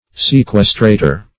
Sequestrator \Seq"ues*tra`tor\, n. [L., one that hinders or